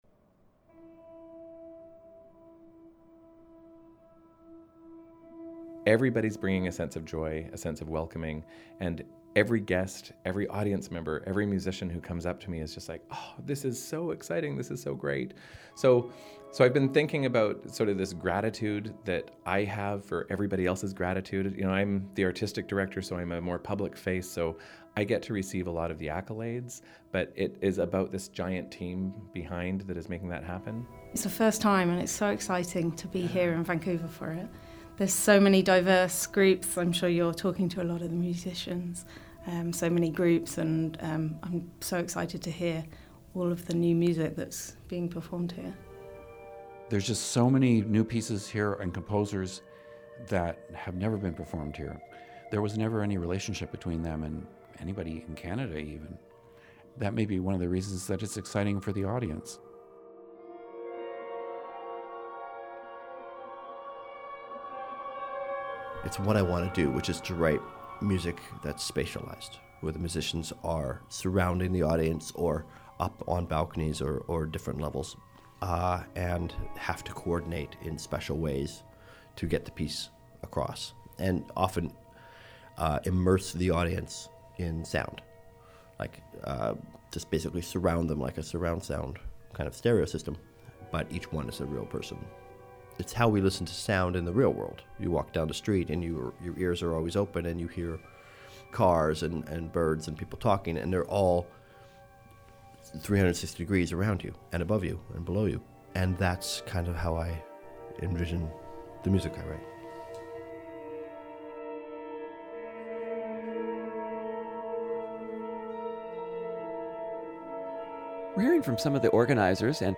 clarinets
percussion
piano
cello